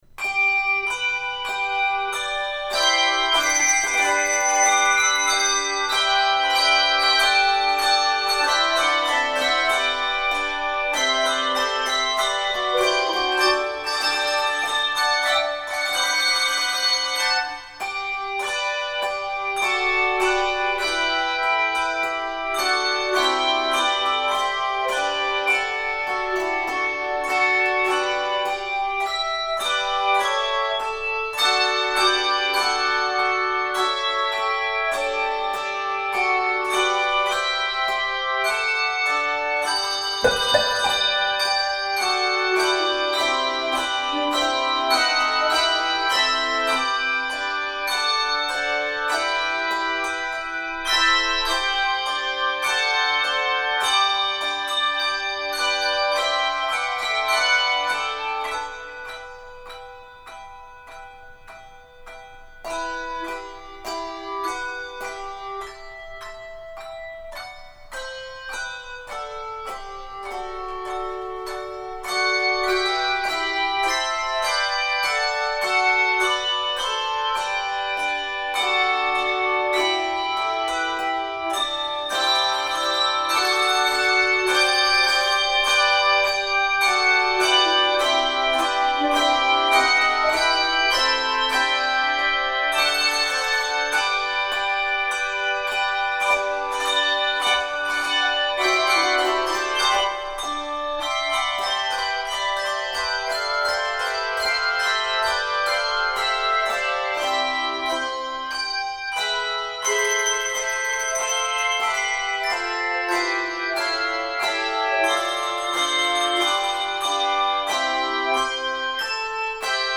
for 4–5 octave handbells
Begins in G Major, then modulates to C Major. 60 measures.